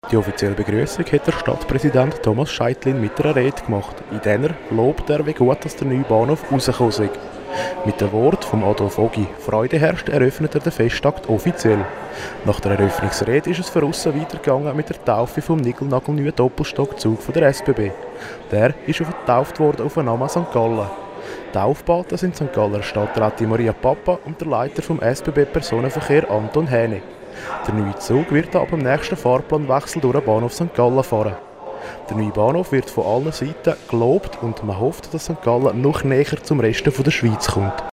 Das Fest hat bereits begonnen und wir sind vor Ort.